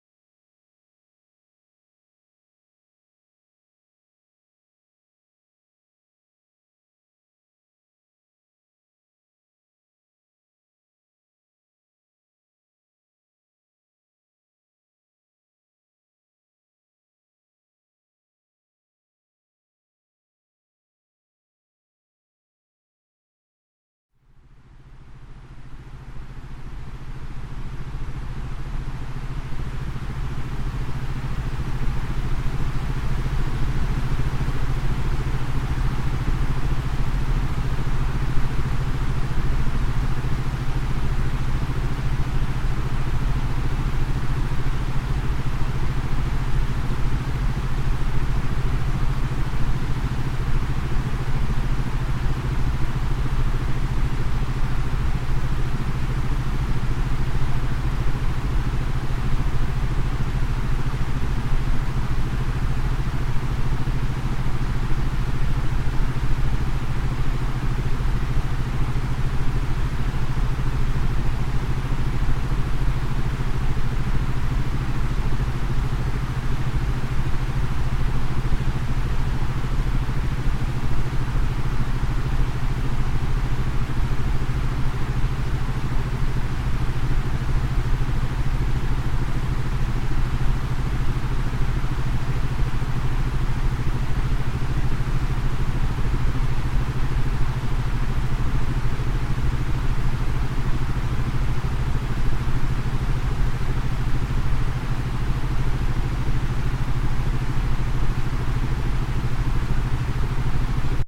Sie erhalten mit dem EINSCHLAFMEISTER eine Mischung von Sounds vorwiegend aus dem Bereich der Isochronen Töne und einem geringen Anteil an Binauralen Beats mit unterschiedlichem Wirkungsziel.
Hörprobe  "Schlafbalsam-Rauschen-Gold" >>